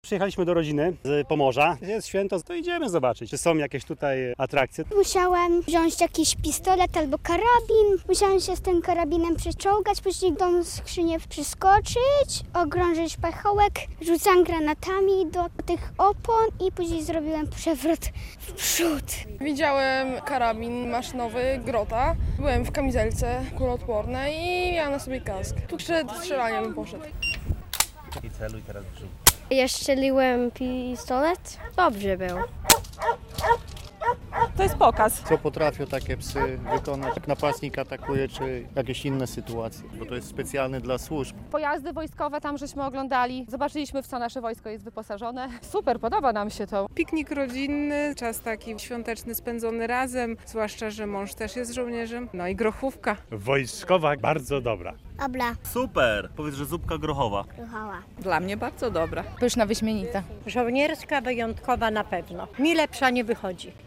Rodzinny piknik militarny "Wojsko na swojsko" w Łomży - relacja